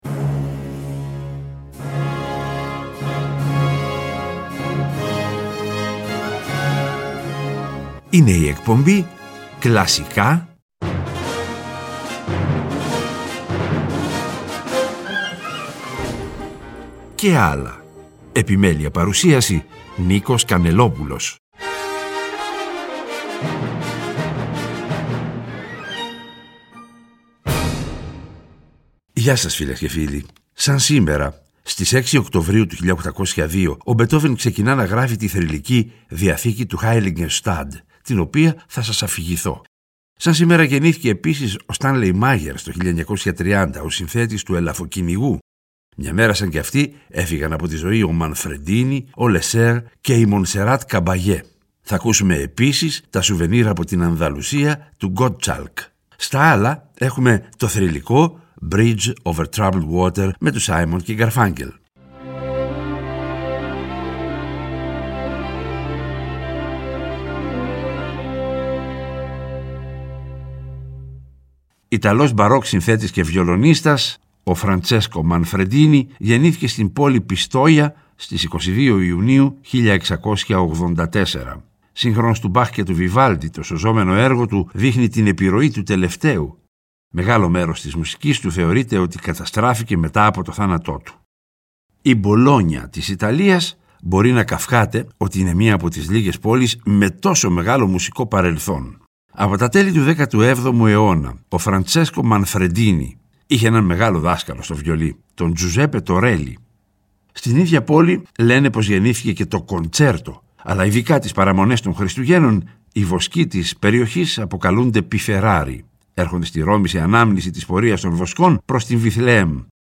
Και, προς το τέλος κάθε εκπομπής, θα ακούγονται τα… «άλλα» μουσικά είδη, όπως μιούζικαλ, μουσική του κινηματογράφου -κατά προτίμηση σε συμφωνική μορφή- διασκ